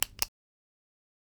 click-sound.ebf4cf26.wav